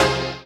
HOUSE046.wav